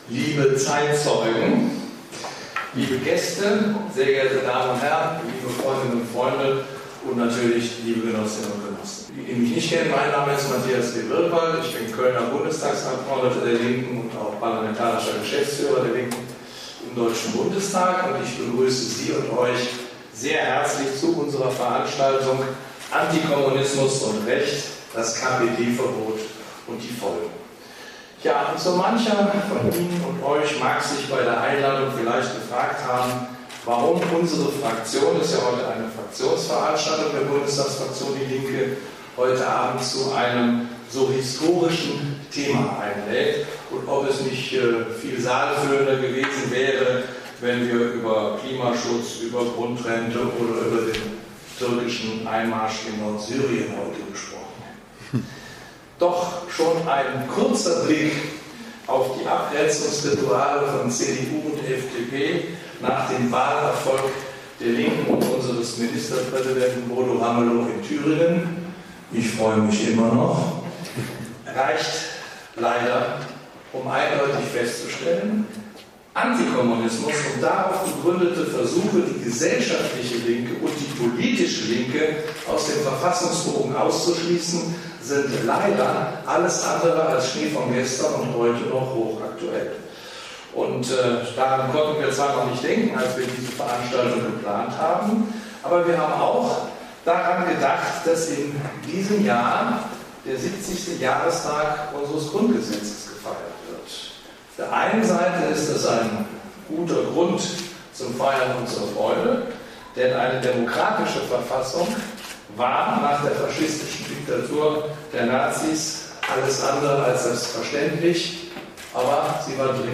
Friedrich Straetmanns, MdB, Richter und Justitiar der Bundestagsfraktion DIE LINKE., und Matthias W. Birkwald, MdB, DIE LINKE., ordneten die Ereignisse historisch und rechtlich ein und diskutierten mit den Zeitzeugen.